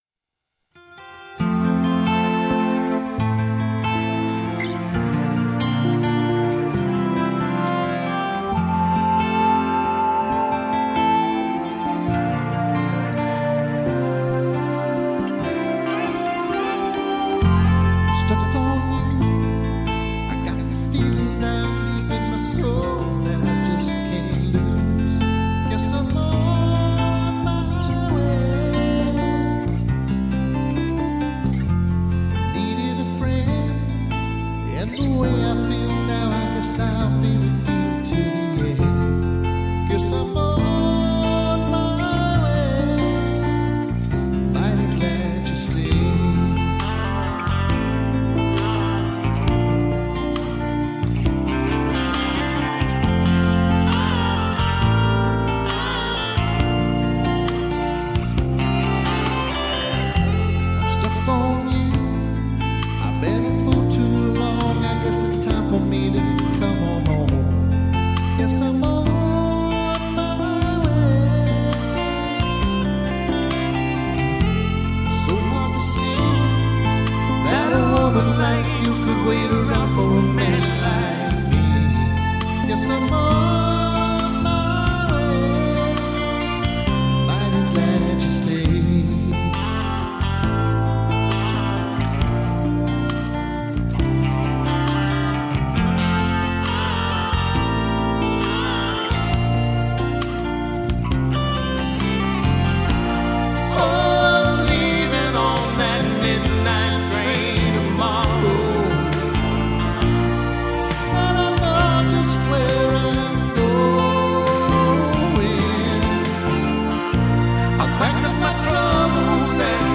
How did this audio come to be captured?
LIVE RECORDINGS DURING ACTUAL SHOW (full songs):